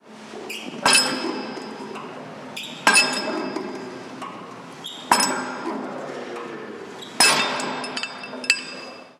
Máquina de pesas 5